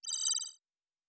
pgs/Assets/Audio/Sci-Fi Sounds/Interface/Data 21.wav at master